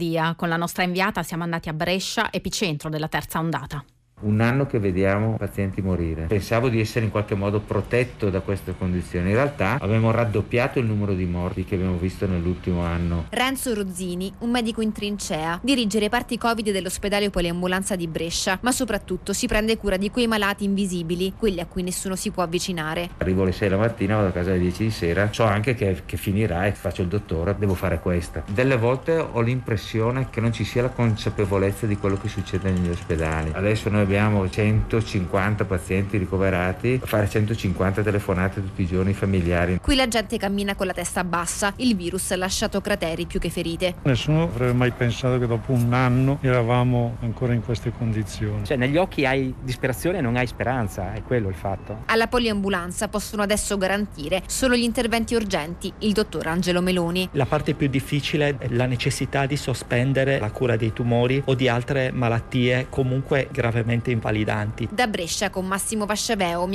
Covid-19: Brescia, epicentro della terza ondata. L'intervista di GR1